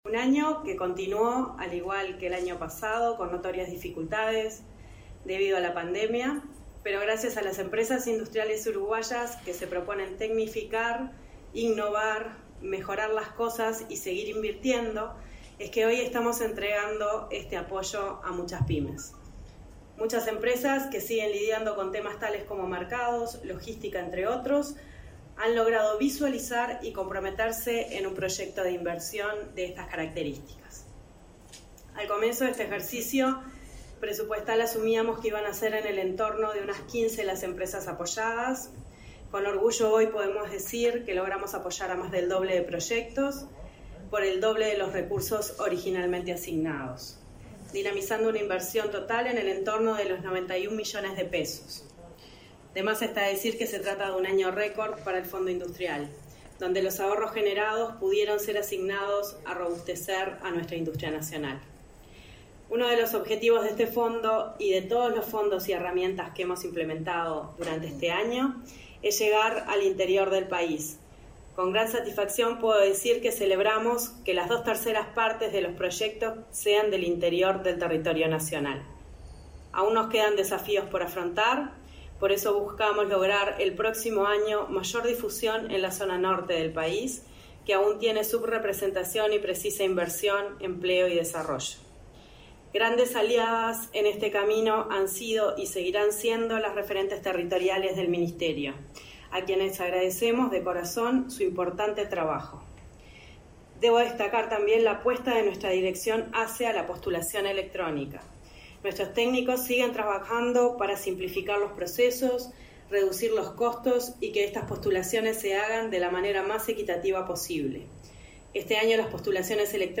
Palabras de autoridades del Ministerio de Industria
La directora nacional de Industrias, Susana Pecoy, y el ministro Omar Paganini participaron este miércoles 22 en el acto de entrega de certificados a